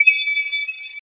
贡献 ） 分类:游戏音效 您不可以覆盖此文件。
se_bonus4.mp3